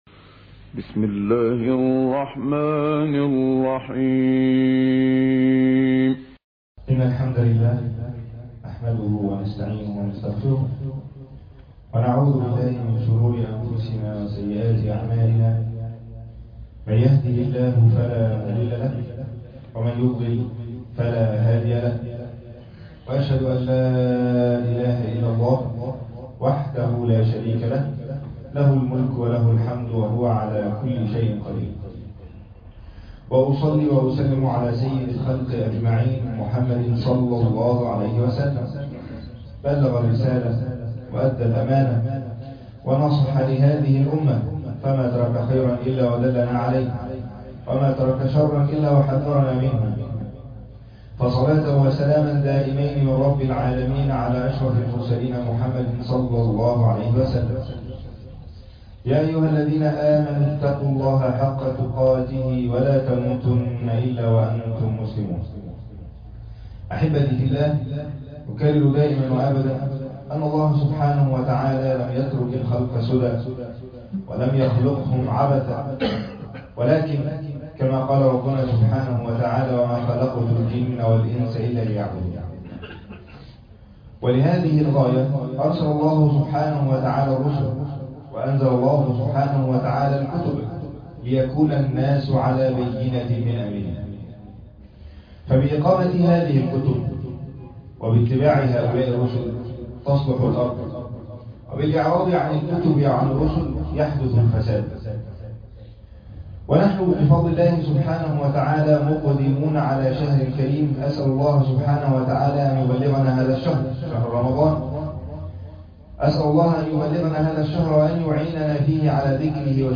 عنوان المادة إنه القرآن - أفلا يتدبرون القرآن خطبة جمعة تاريخ التحميل الأربعاء 29 يونيو 2022 مـ حجم المادة 14.53 ميجا بايت عدد الزيارات 473 زيارة عدد مرات الحفظ 162 مرة مشاهدة المادة حفظ المادة اضف تعليقك أرسل لصديق